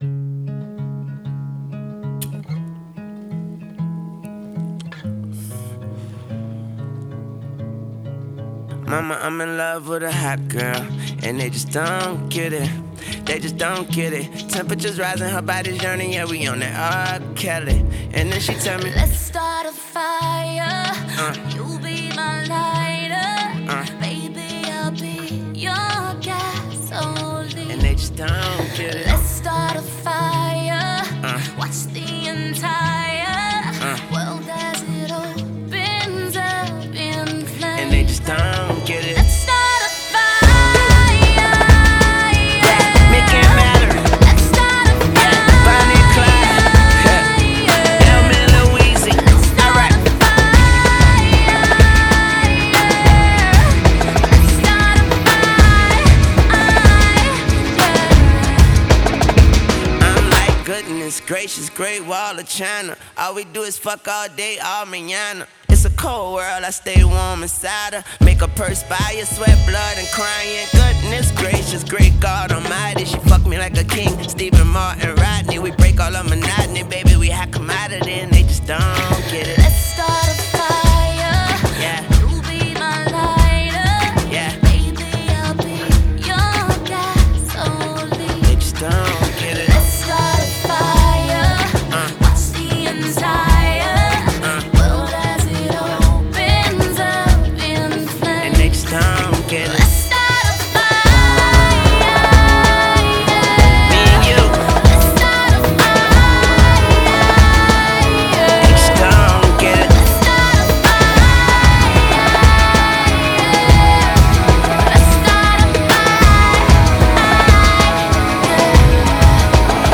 has the production and chorus of a harmless pop song